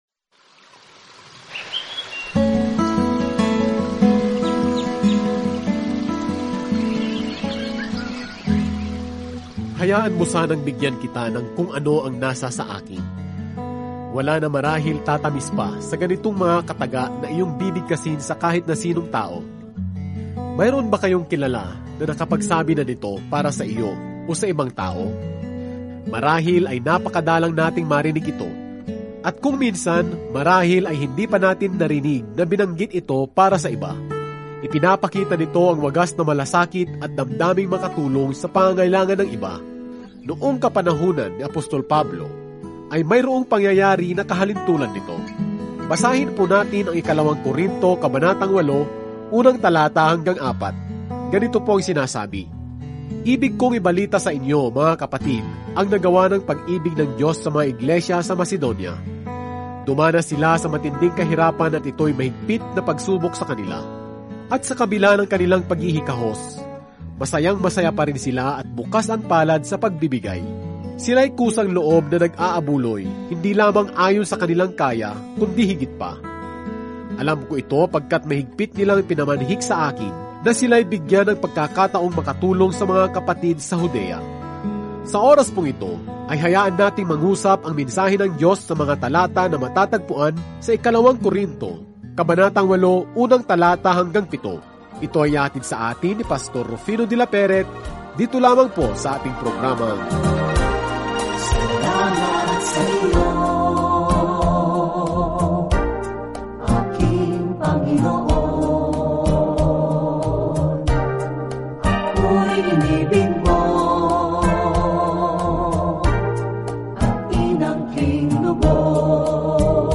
audio study